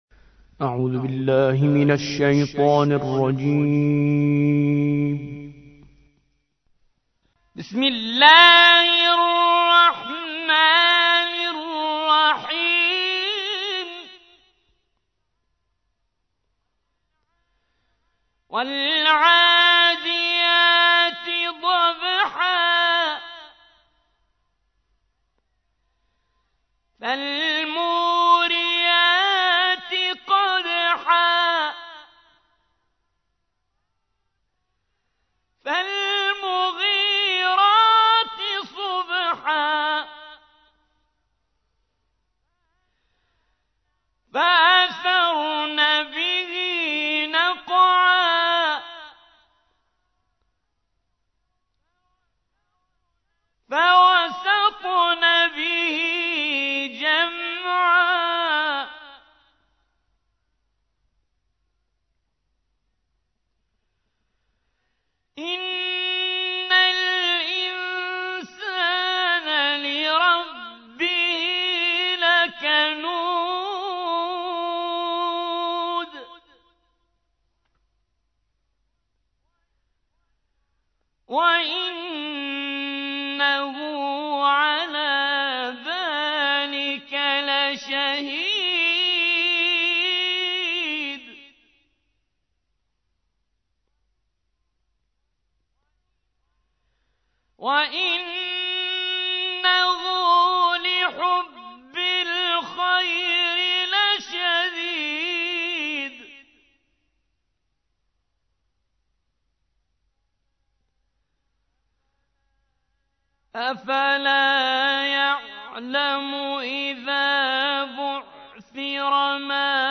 100. سورة العاديات / القارئ